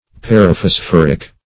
Search Result for " paraphosphoric" : The Collaborative International Dictionary of English v.0.48: Paraphosphoric \Par`a*phos*phor"ic\, a. [Pref. para- + phosphoric.]